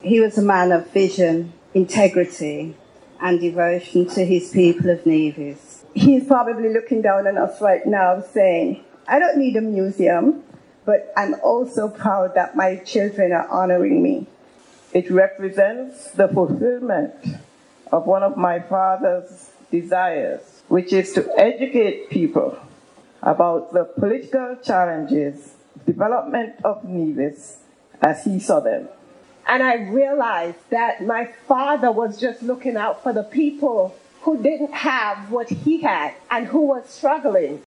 At the Saturday’s Ribbon Cutting Ceremony, his children and other family members expressed their delight in at opening of the museum.